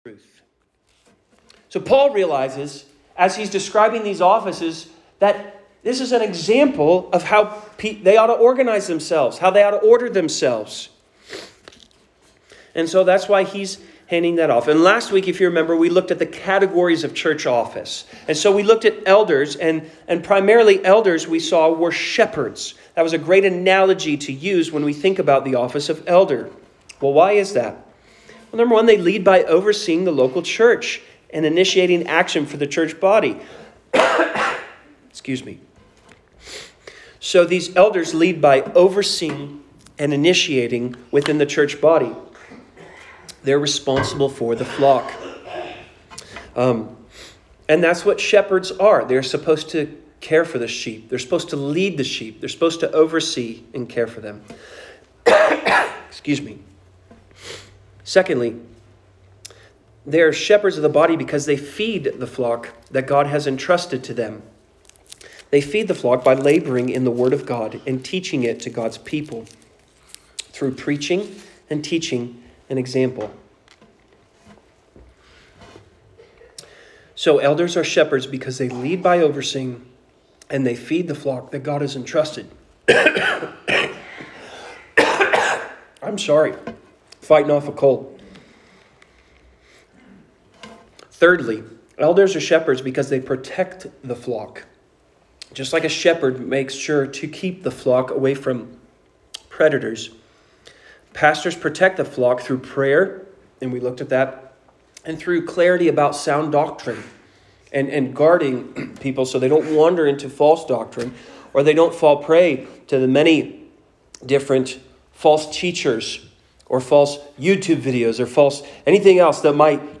Sermons | Grace Gospel Church